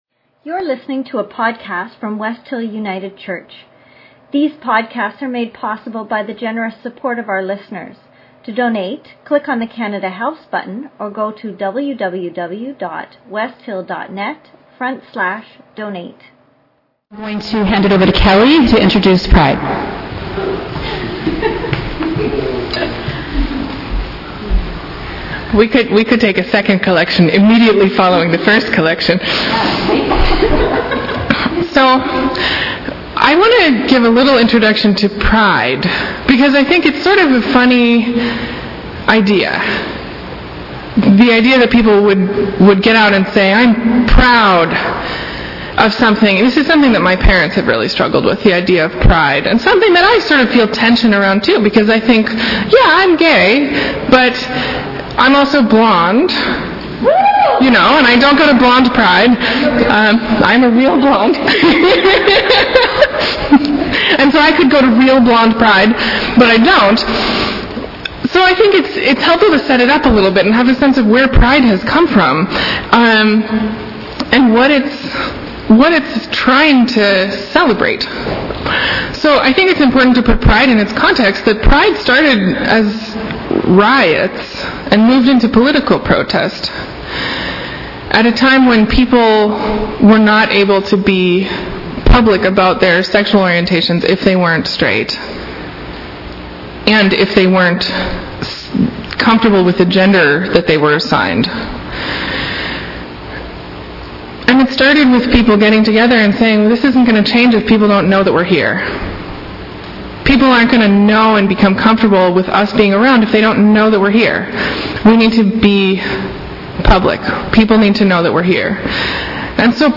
June 29, 2014 - Pride service — West Hill United